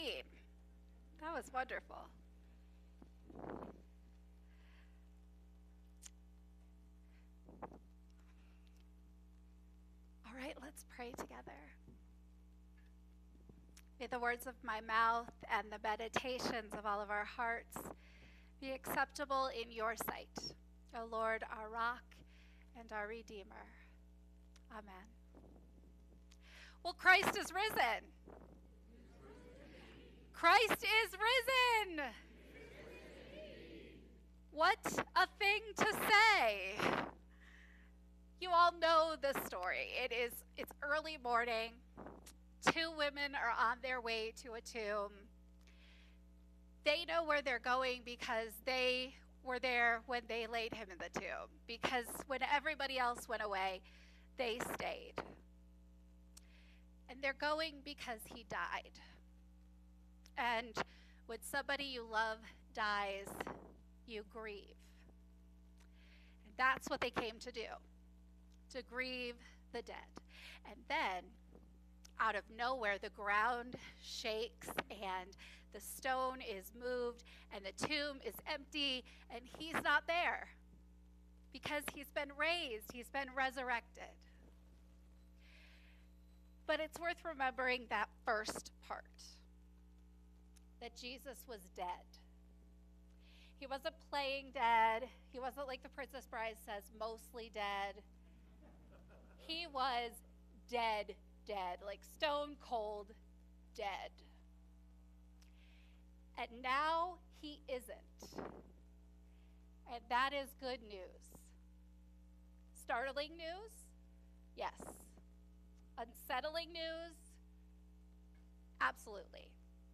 Sermons | United Methodist Church of Evergreen